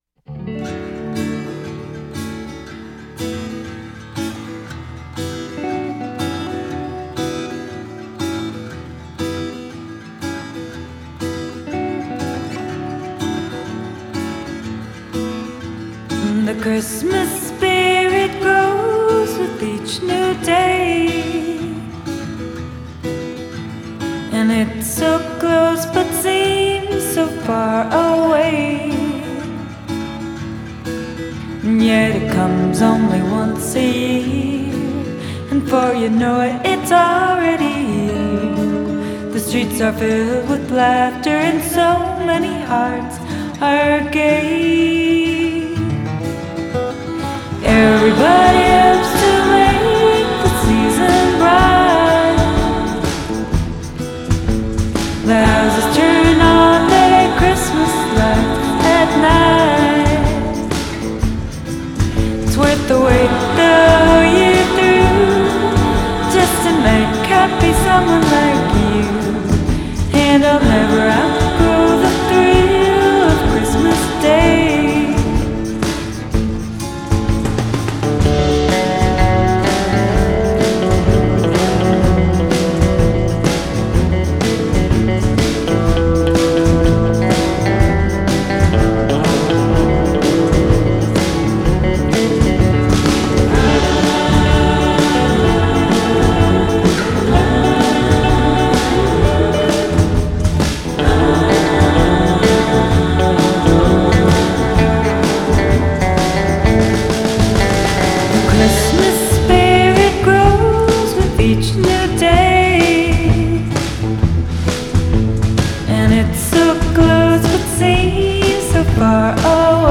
Style: Indie Folk